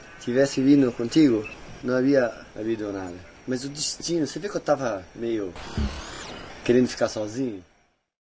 Recording at The Posada MIRANTE KonTik PraiaBranca, Sao Paulo, BrazilMD MZR50 Mic ECM907
标签： 场记录 mzr50 ecm907 话音 语音 聊天 雄性
声道立体声